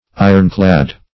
Ironclad \I"ron*clad`\, a.